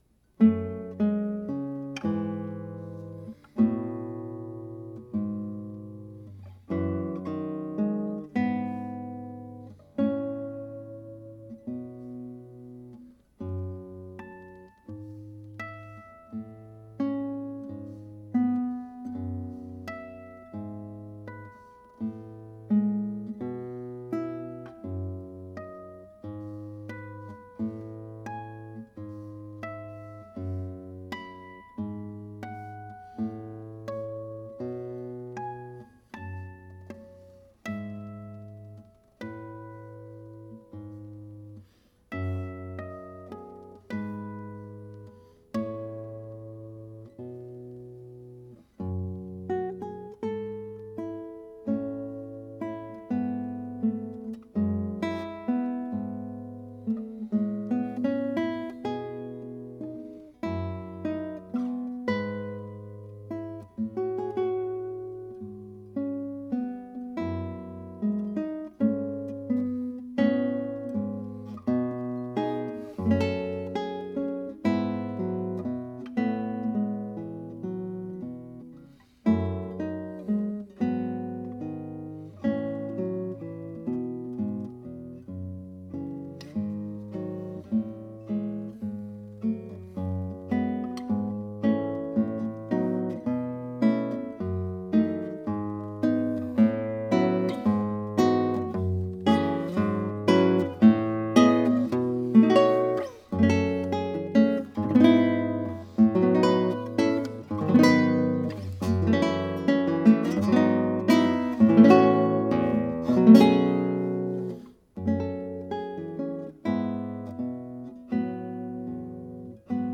21 short etude-like movements, total length ca. 27 minutes, written in 2013.